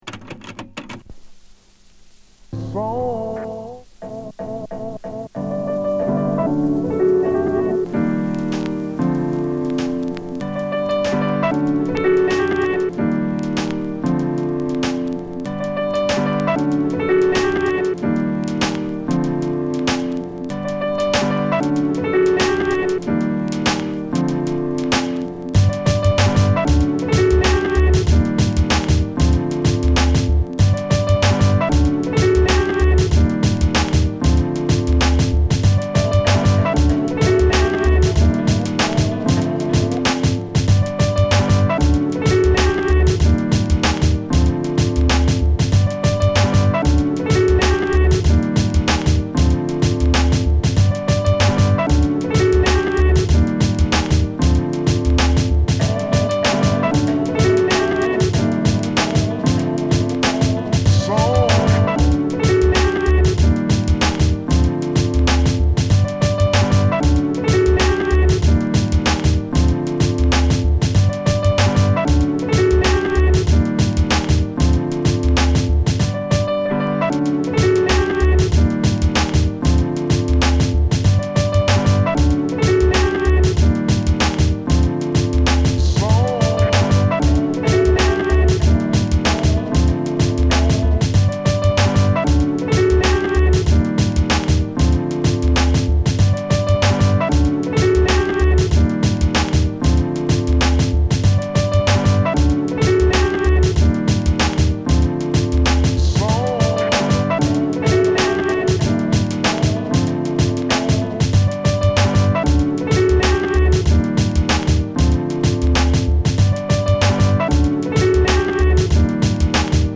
ジャズ・ピアノ使い輝く
ジャズ・サンバ・ムードの
ジャズ、ソウル・フィーリング溢れるメロウブーン・バップを中心に21トラックを収録！